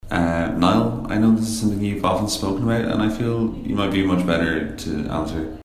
Tags: interview